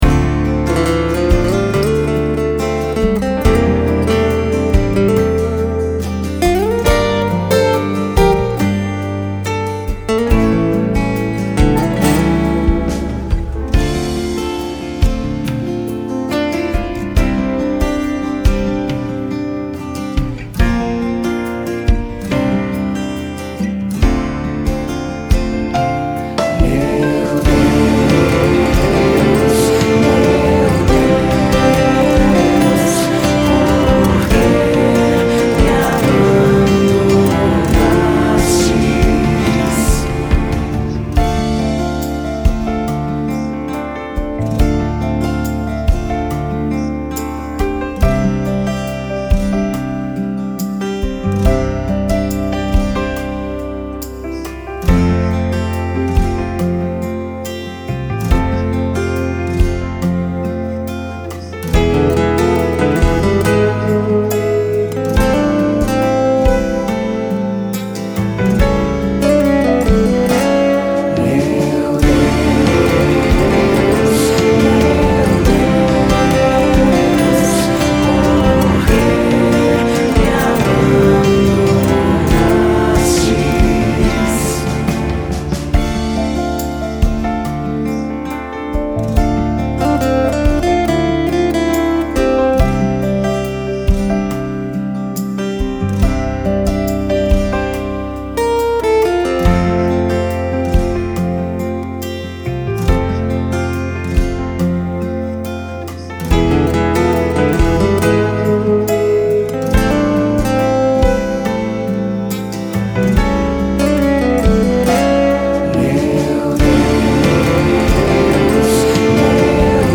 PlayBack (música)